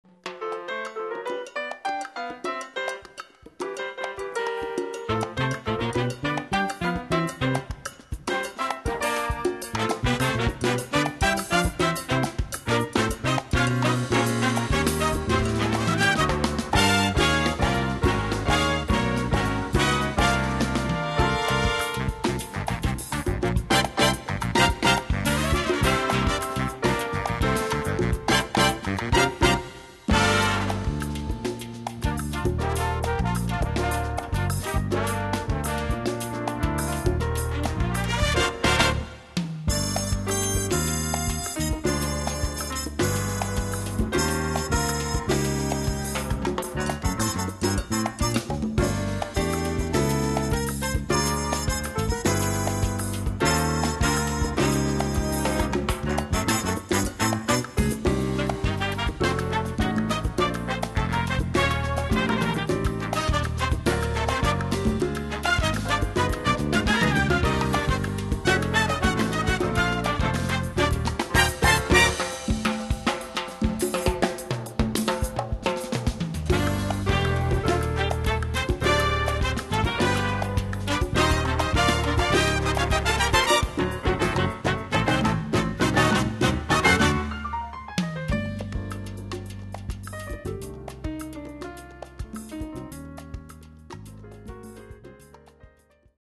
Category: little big band
Style: mambo
Solos: piano, trombone, percussion.